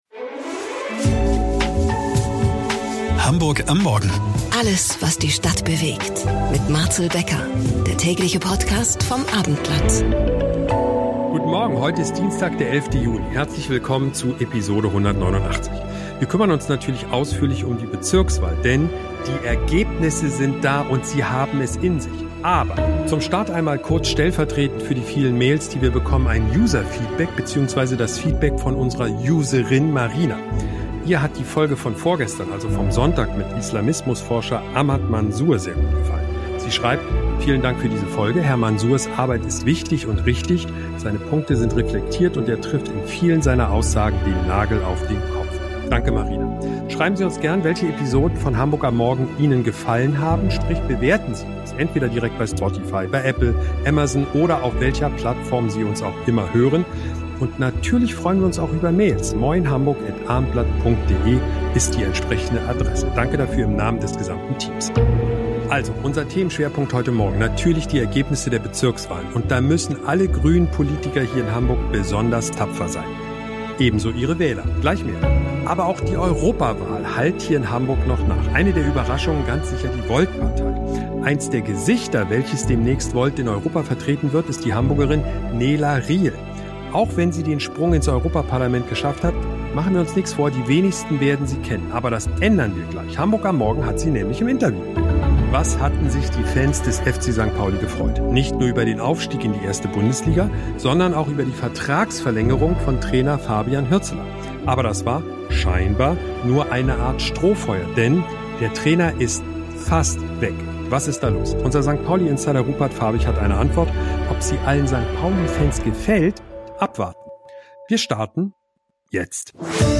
Wir experimentieren mit einer neuen Aufnahmetechnik:( Mehr